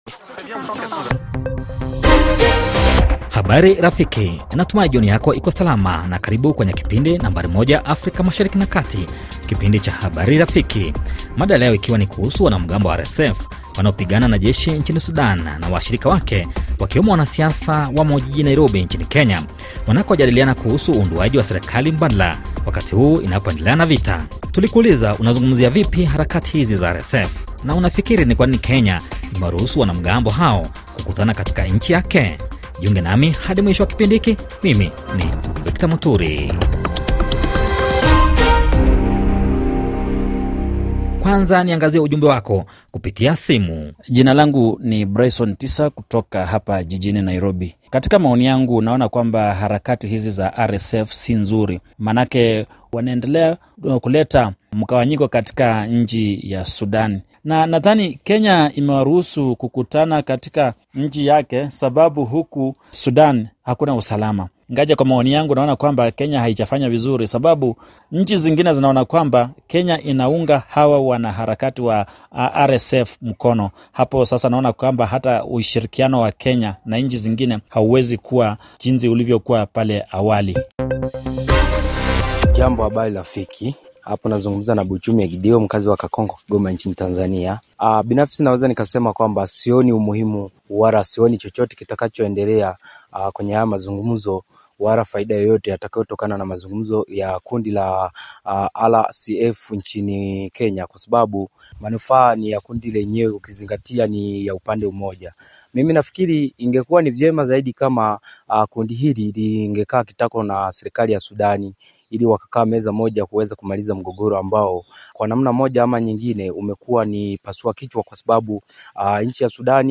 Makala ya kila siku yanayompa fursa msikilizaji kutoa maoni yake juu ya habari zilizopewa uzito wa juu kwa siku husika. Msikilizaji hushiriki kwa kutuma ujumbe mfupi pamoja na kupiga simu.